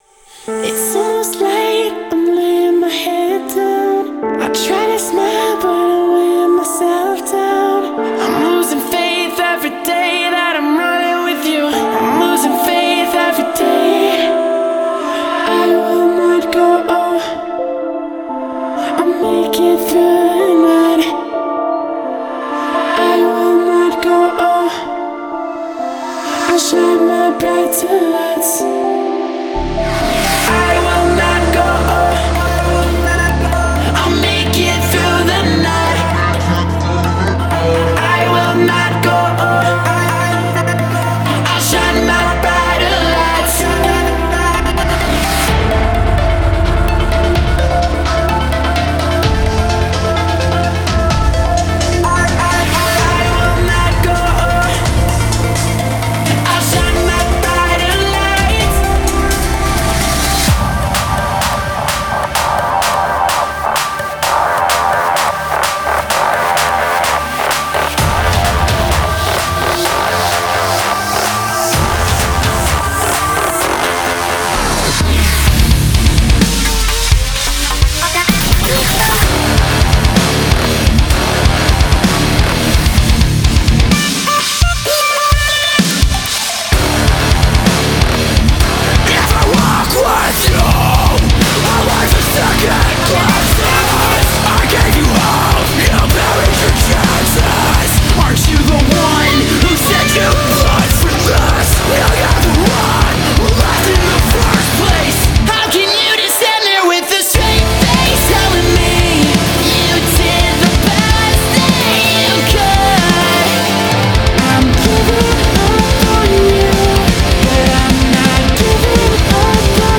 BPM64-128
MP3 QualityMusic Cut